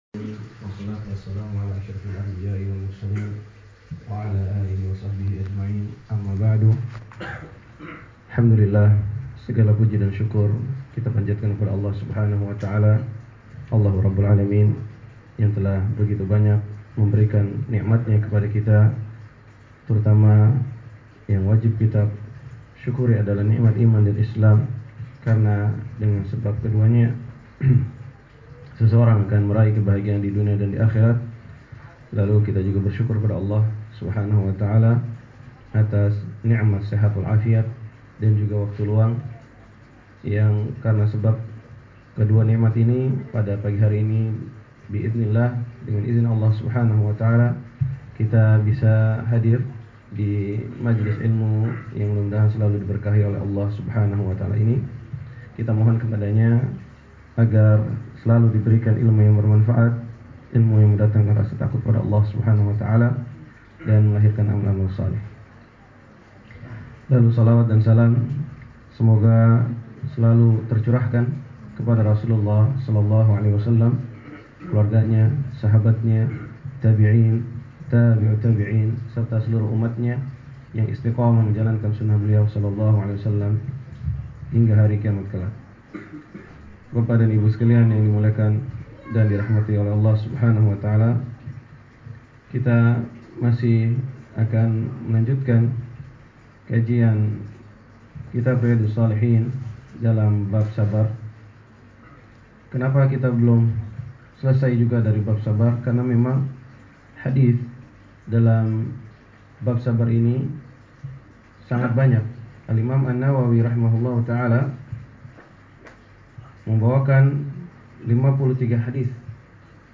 Kajian Kitab Riyadhus Shalihin Bab Sabar